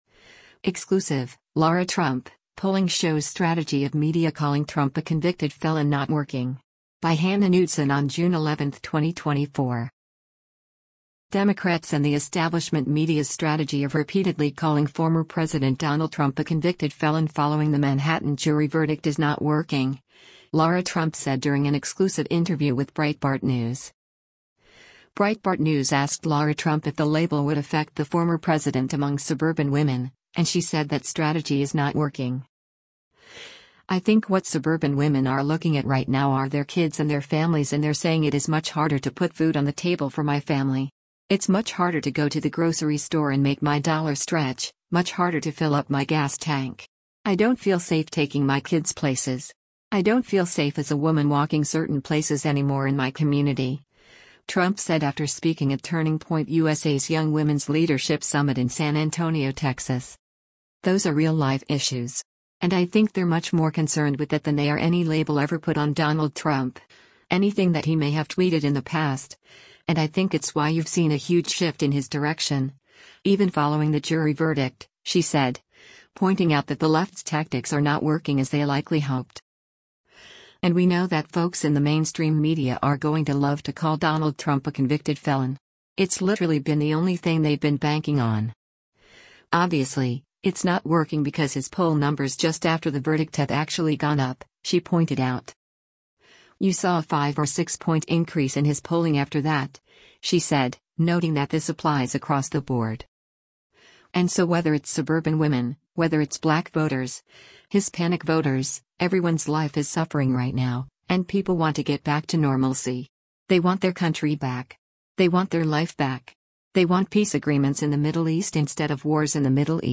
Democrats’ and the establishment media’s strategy of repeatedly calling former President Donald Trump a “convicted felon” following the Manhattan jury verdict is not working, Lara Trump said during an exclusive interview with Breitbart News.